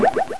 garbage_shattering.wav